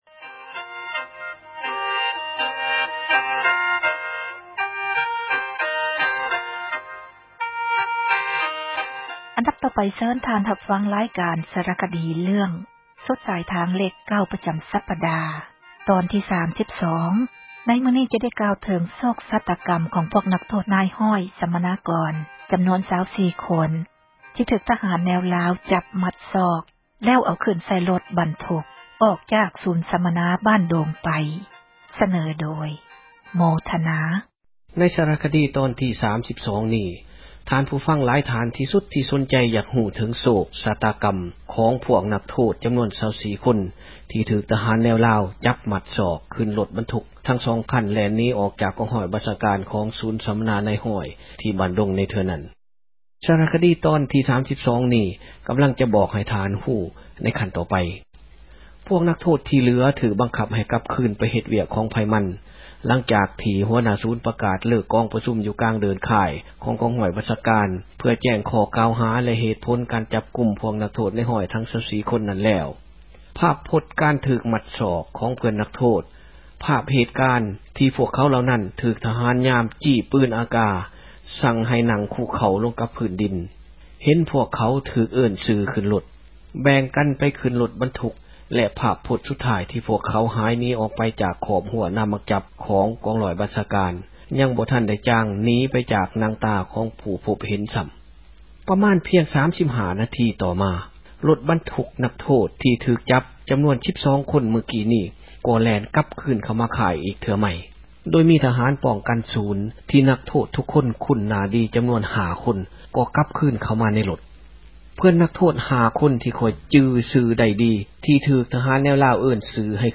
ເຊີນທ່ານຮັບຟັງ ຣາຍການ ສາຣະຄະດີ ເຣື້ອງ ”ສຸດສາຍທາງເລຂ 9” ປະຈຳສັປດາ ຕອນທີ 32. ໃນມື້ນີ້ຈະໄດ້ ກ່າວເຖິງໂຊກ ຊະຕາກັມ ຂອງພວກນັກໂທດ ນາຍຮ້ອຍສັມມະນາກອນ ຈຳນວນ 24 ຄົນ ທີ່ຖືກທະຫານ ແນວລາວ ຈັບມັດສອກ ແລ້ວເອົາຂື້ນໃສ່ ຣົຖບັນທຸກ ອອກຈາກສູນສັມມະນາ ”ບ້ານດົງ” ໄປ.